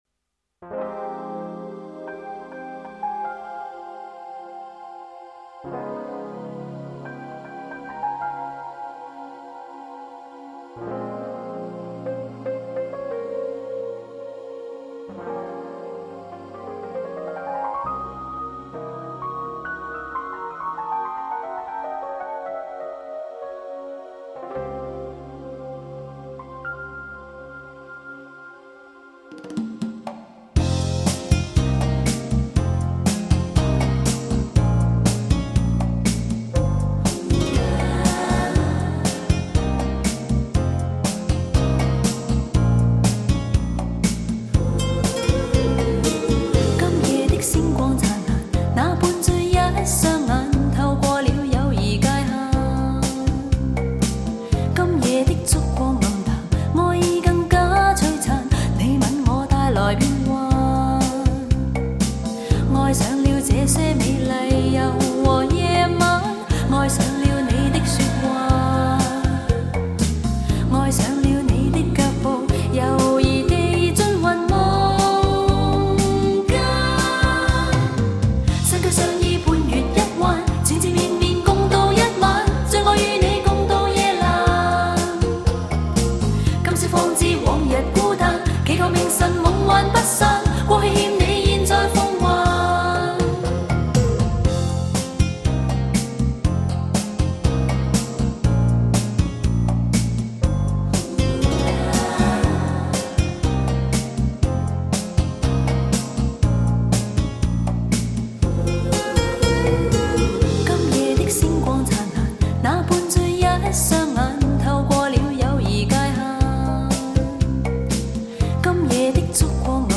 节奏明快、歌词通俗易懂，听后耐人寻味，尤以《匆匆》为是，时光如白驹过隙，